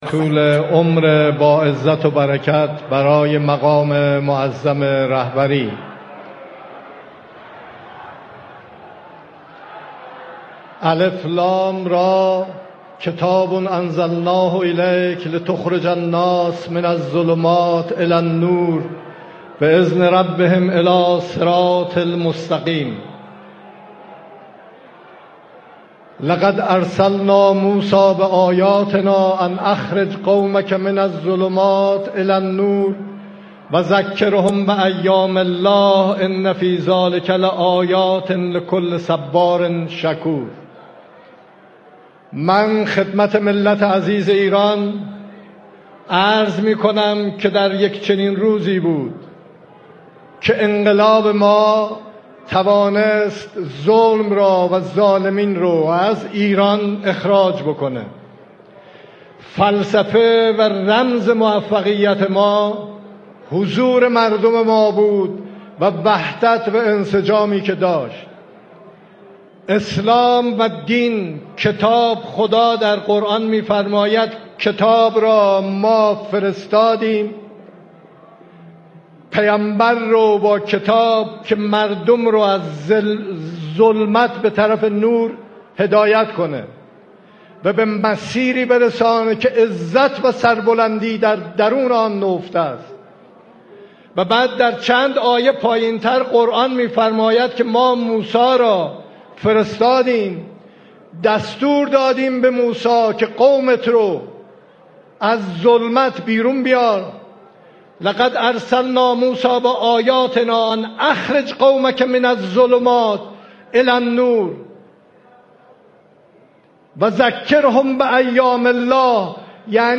در چنین روزی انقلاب توانست ظلم و ظالمان را از ایران خارج كند به گزارش پایگاه اطلاع رسانی رادیو تهران، مسعود پزشكیان رئیس جمهوری ایران در مراسم یوم‌الله 22 بهمن 1403 با یادآوری اینكه به مردم عزیز ایران عرض می‌كنم كه در چنین روزی انقلاب توانست ظلم و ظالمان را از ایران خارج كند، گفت: فلسفه و رمز موفقیت‌مان حضور مردم و وحدت و انسجام بود.